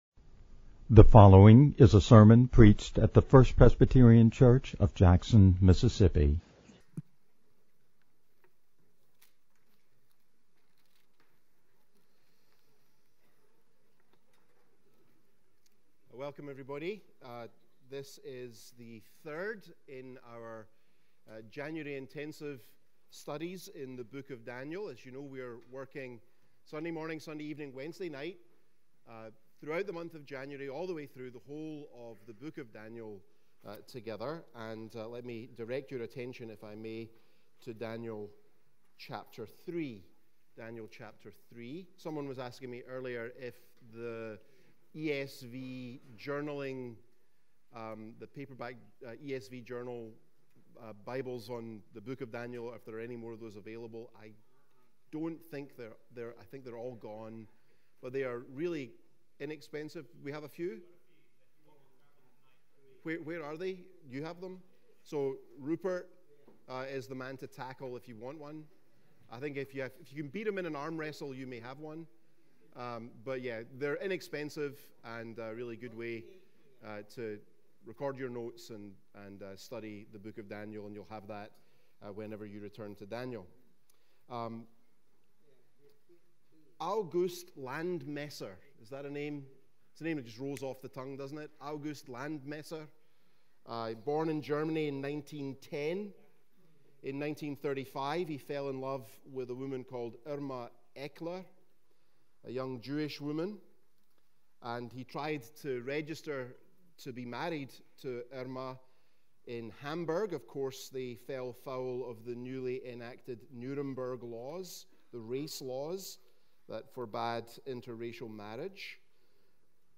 January-8-2025-Wednesday-Evening-Sermon-audio-with-intro.mp3